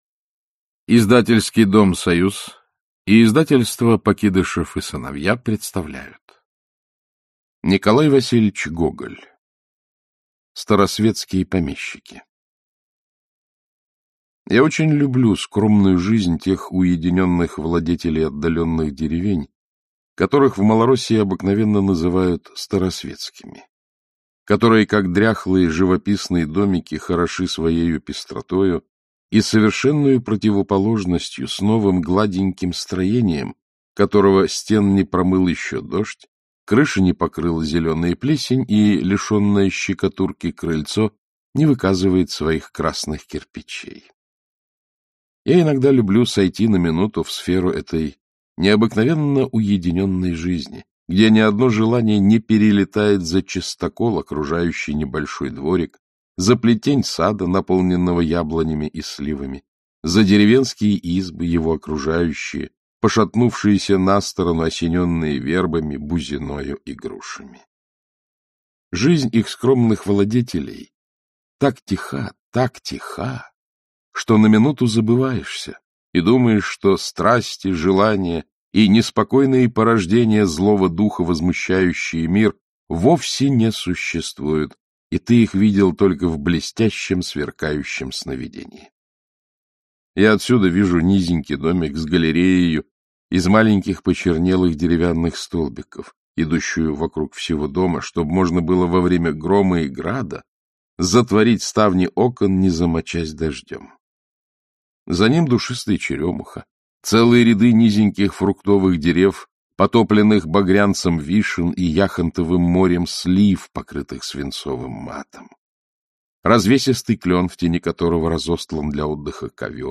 Аудиокнига Старосветские помещики | Библиотека аудиокниг
Aудиокнига Старосветские помещики Автор Николай Гоголь Читает аудиокнигу Александр Клюквин.